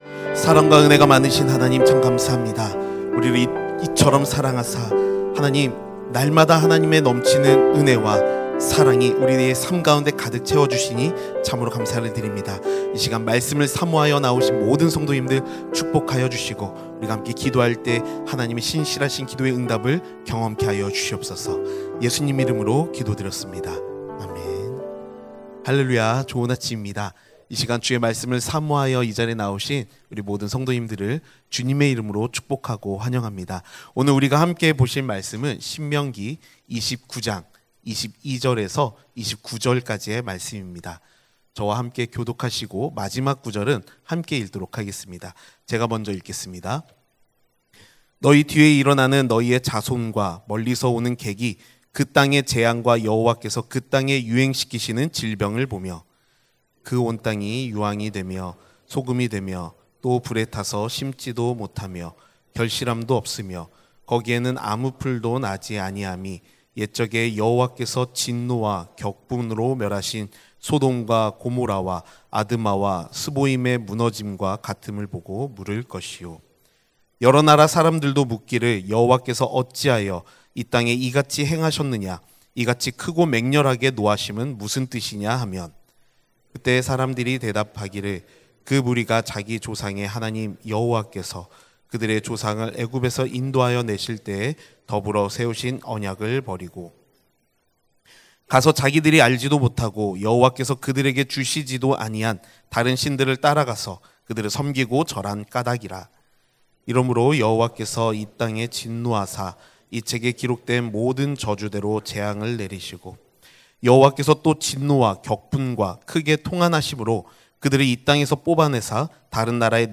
[새벽예배]